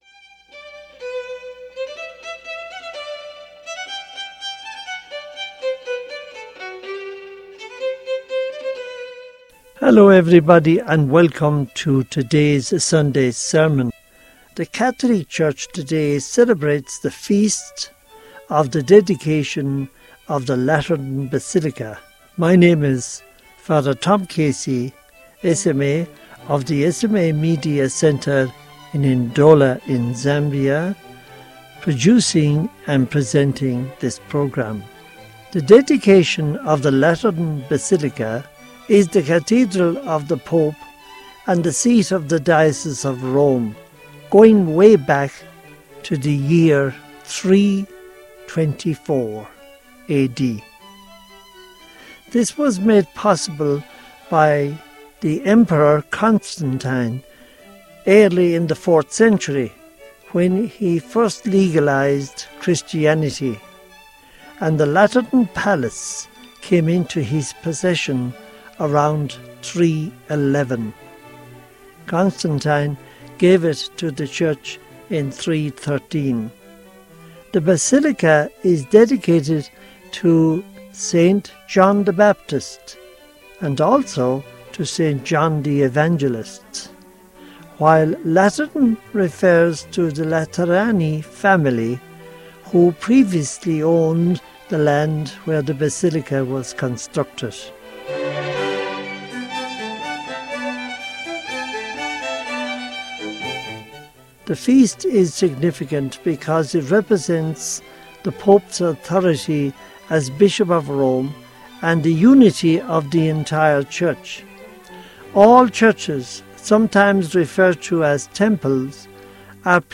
Homily for the Feast of the Dedication of the Lateran Basilica | Society of African Missions